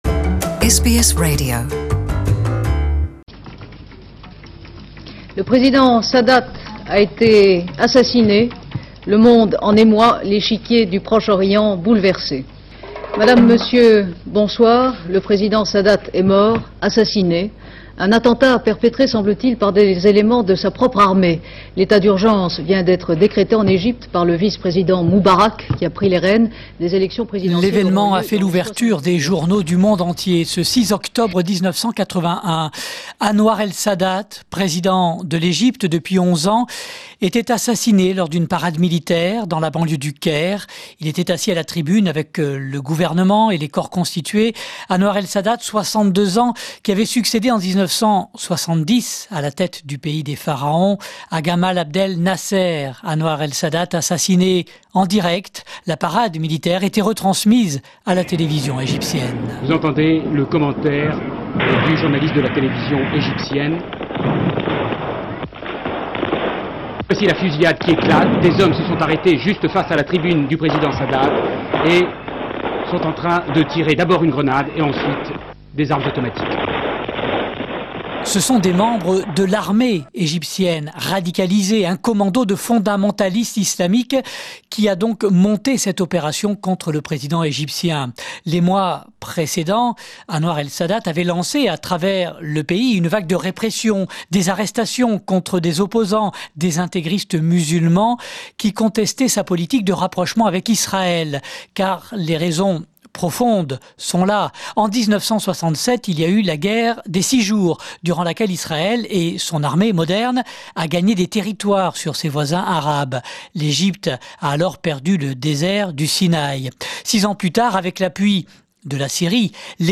Retour sur cet événement marquant de l’histoire avec les archives de l'INA.